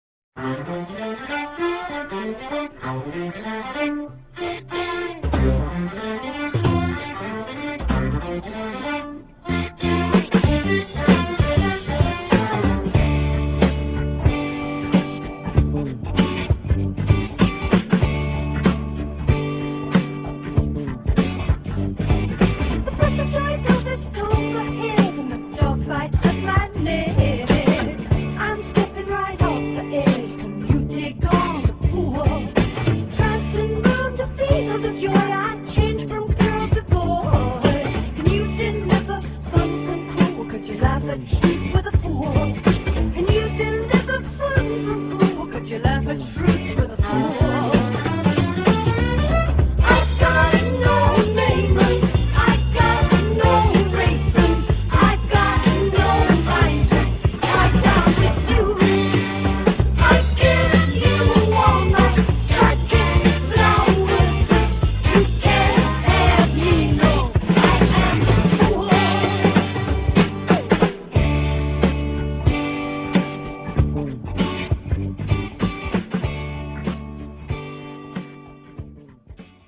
stereo, 4.0 Khz, 20 Kbps, file size: 196 Kb